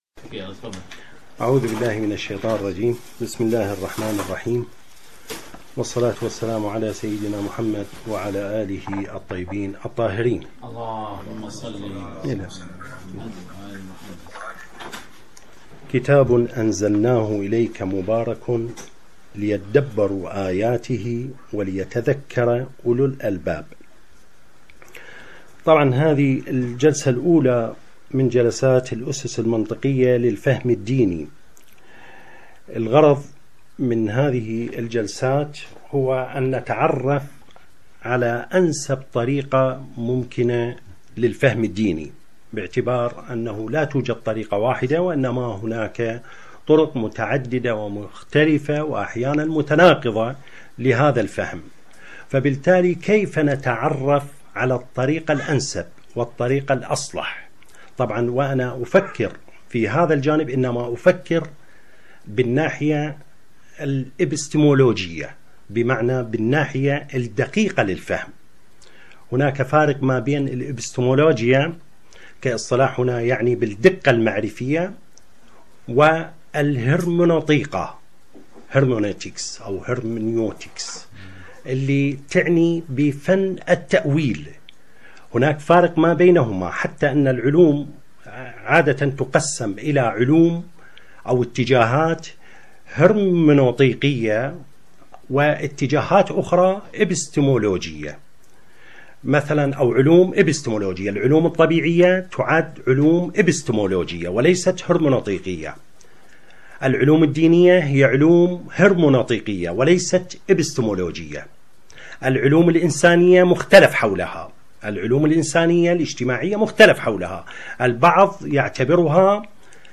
dars_1_alfahm_aldiny_hurmoontiqiya_alnas.mp3